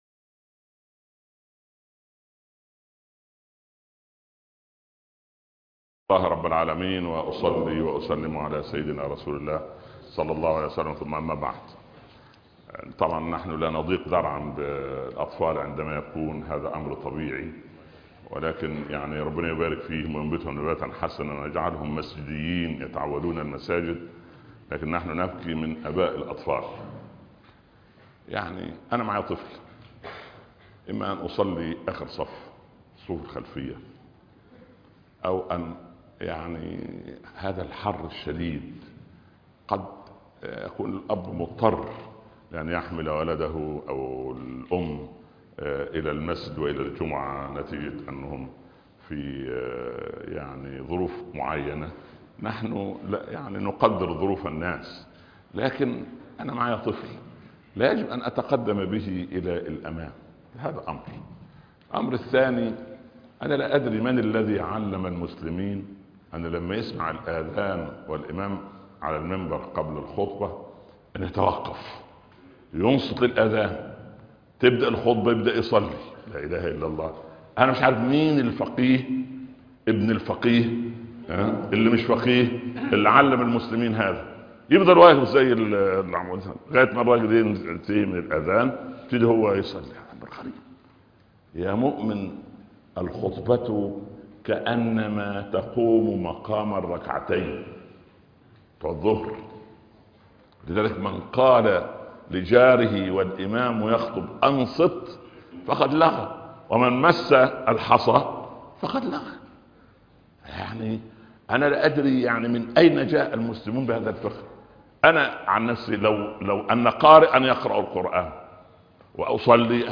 لا تصاحب الهم ( درس الجمعة ) - الشيخ عمر بن عبدالكافي